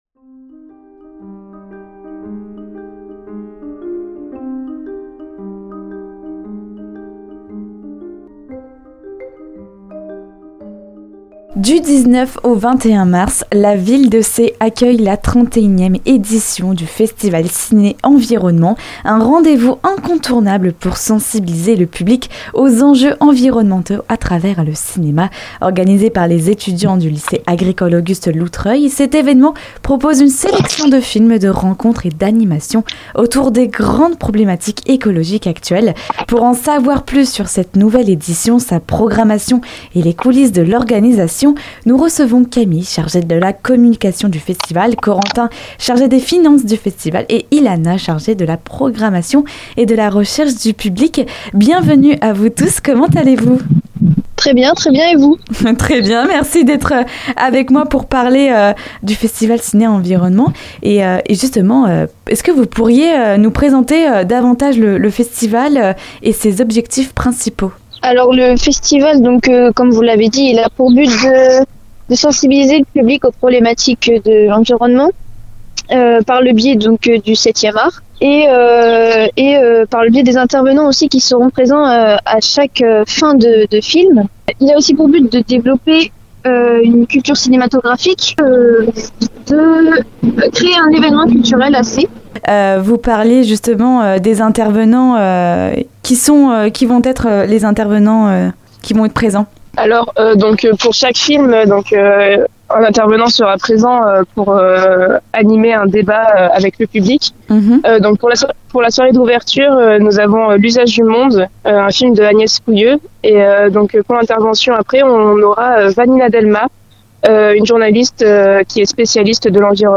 écoutez l'interview complète